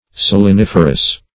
Saliniferous \Sal`i*nif"er*ous\
saliniferous.mp3